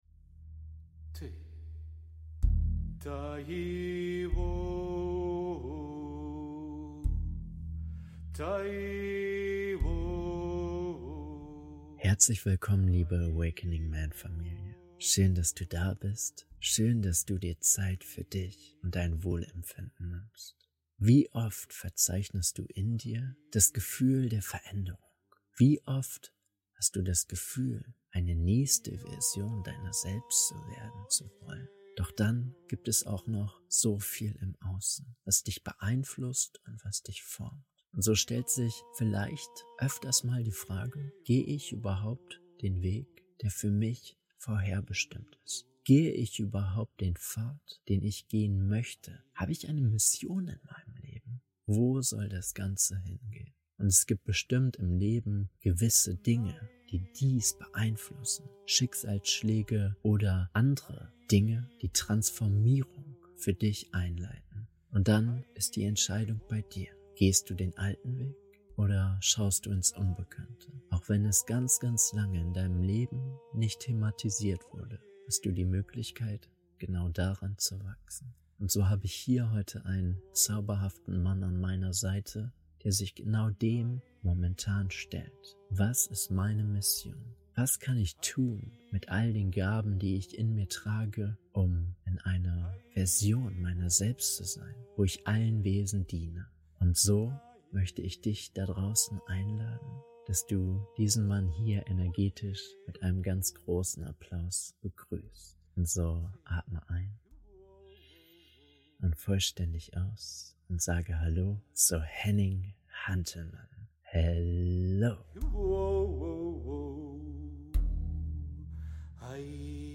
Entdecke deine Mission auf Erden - Interview